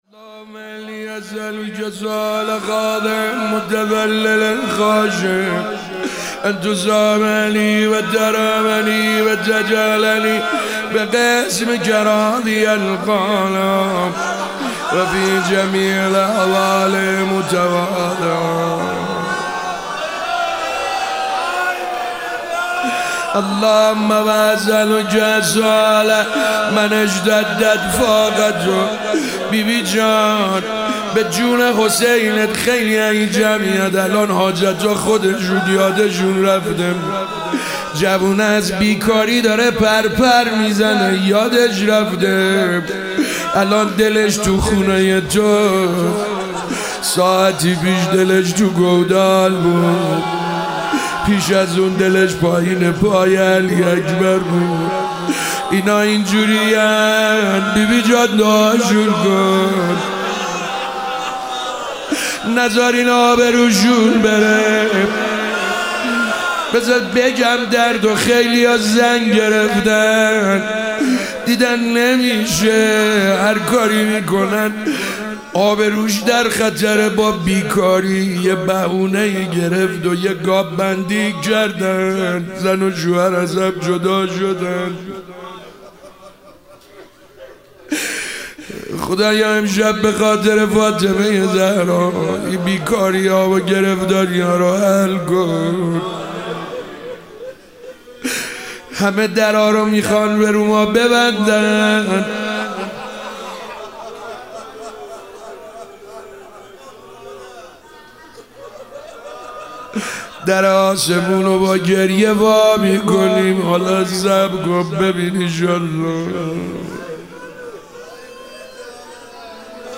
شب 9 رمضان 97- مناجات با خدا